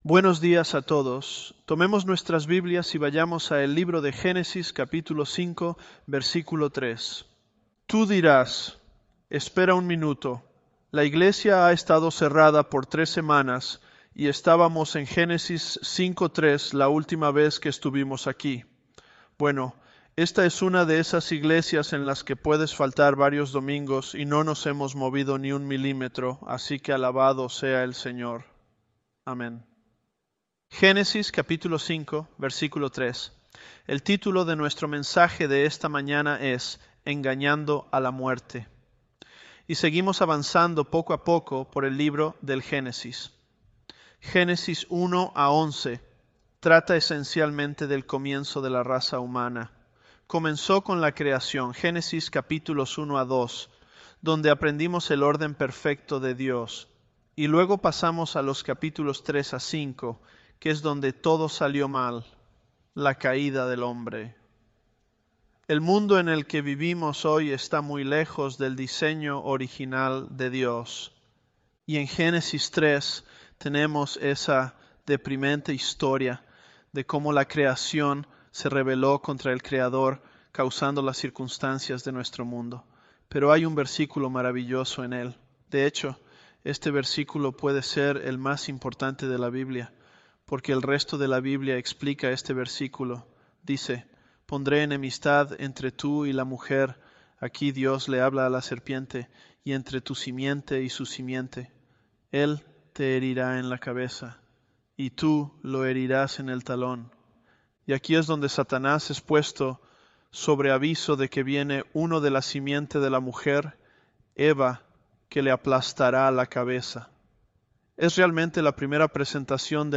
ElevenLabs_Genesis-Spanish023.mp3